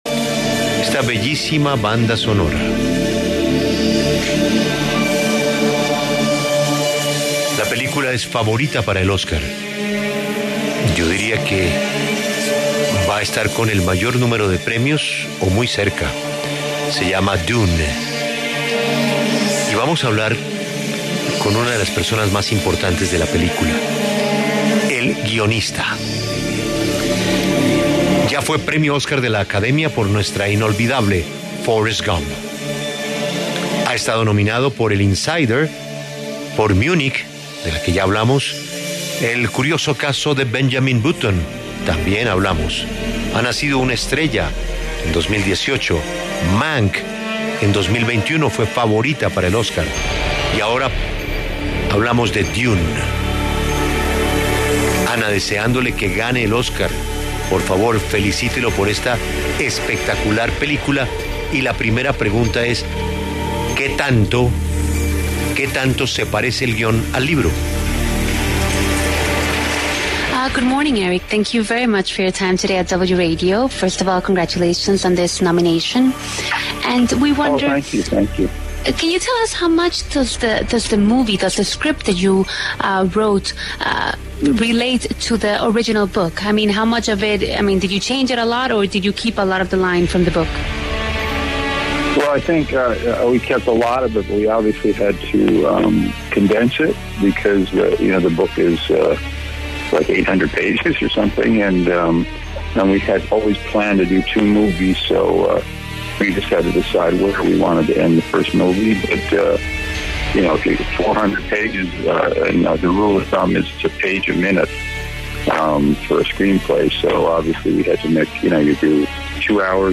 Eric Roth, guionista estadounidense nominado a los Premios Óscar a Mejor Guion Adaptado por Dune, habló en La W sobre su producción.